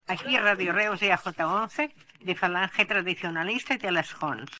Identificació de l'emissora (recreació feta posteriorment)